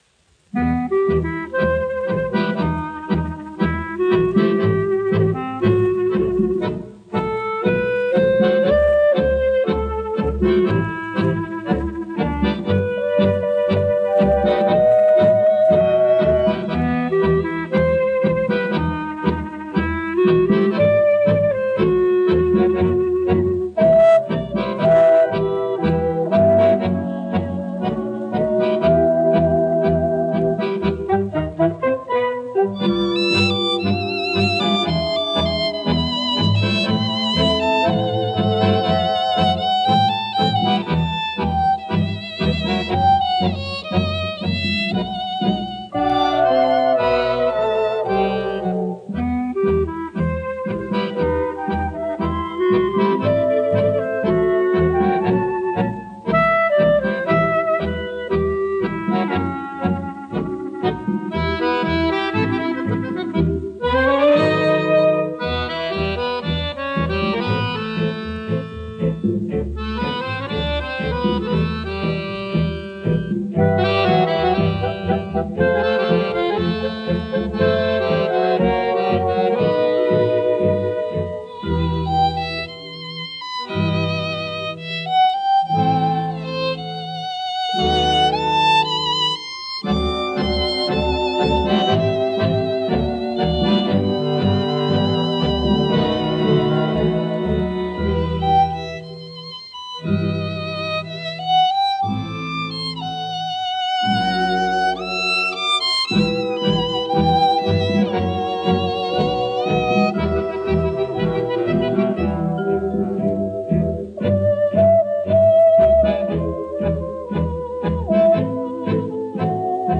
танго в том же исполнении, но без пения.